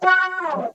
VOC FALLOFF2.wav